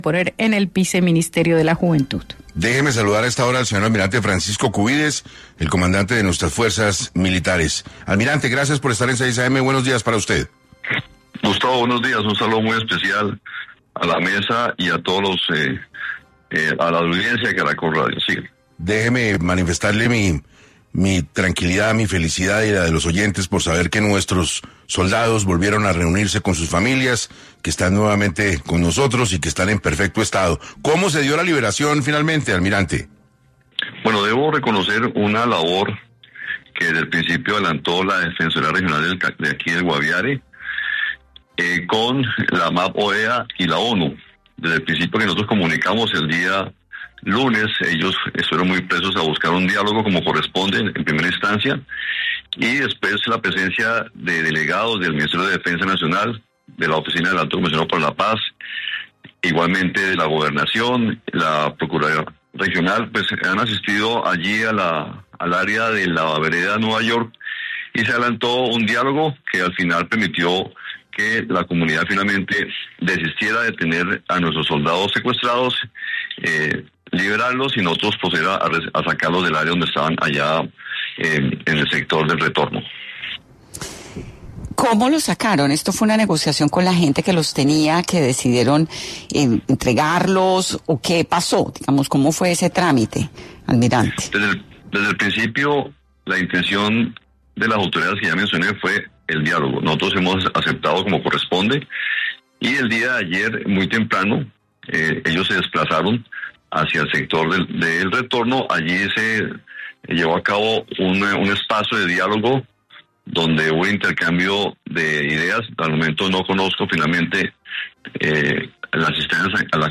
El Almirante Francisco Cubides, comandante de las Fuerzas Militares habla en Caracol Radio sobre la liberación de los más de 30 militares secuestrados en Guaviare